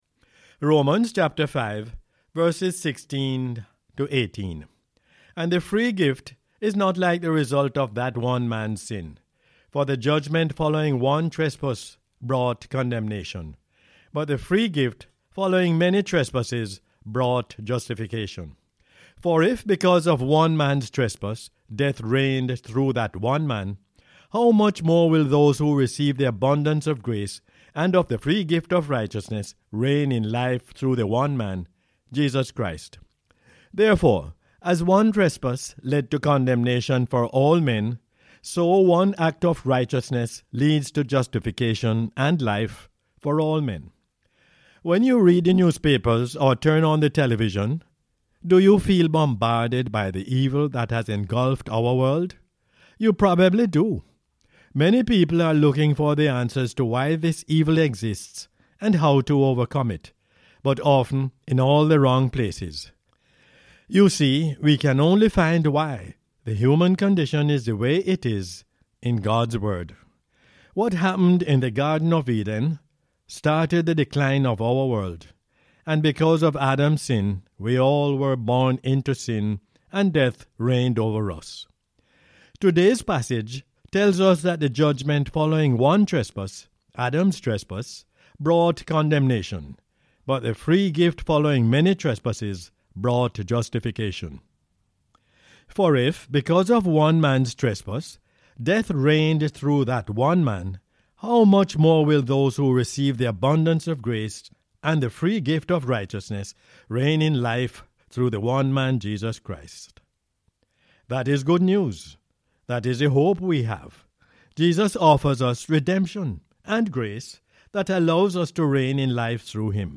Romans 5:16-18 is the “Word For Jamaica” as aired on the radio on 19 February 2021.